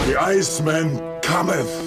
One of the most iconic Arnold Schwarzenegger quotes.